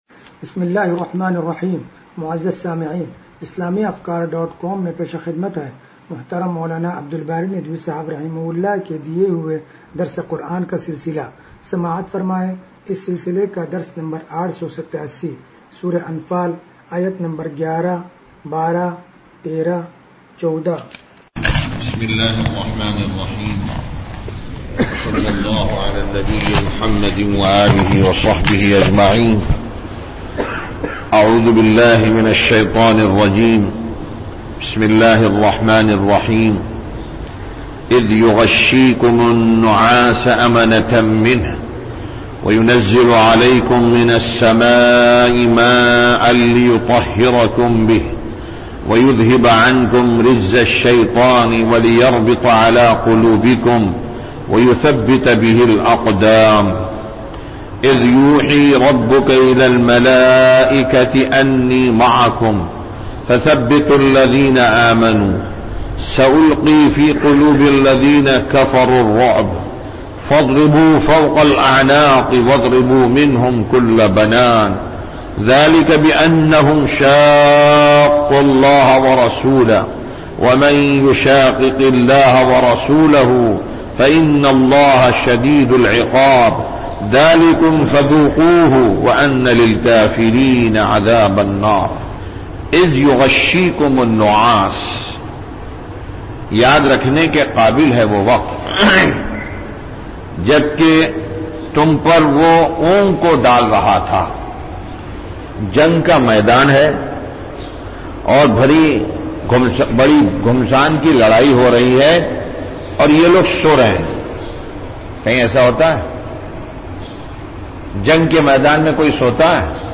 درس قرآن نمبر 0887